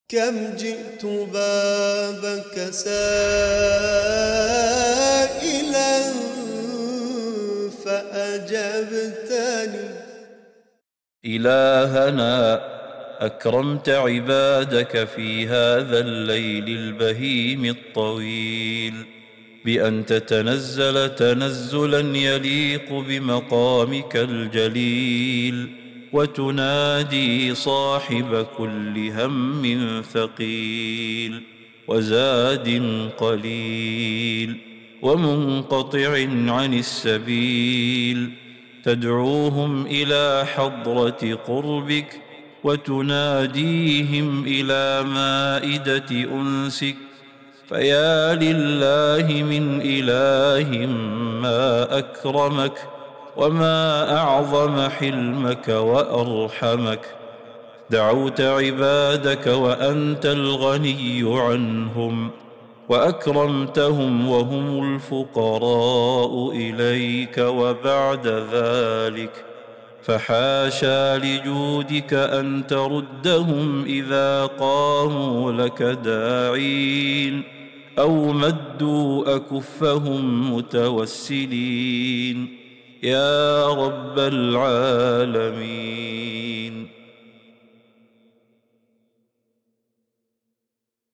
دعاء خاشع يتناسب مع أوقات السحر وهدوء الليل، يمجّد فيه الداعي كرم الله وعظمته وحلمه على عباده الفقراء إليه. يعبر النص عن حالة من الأنس والمناجاة والتوسل إلى الله تعالى بقبول الدعاء.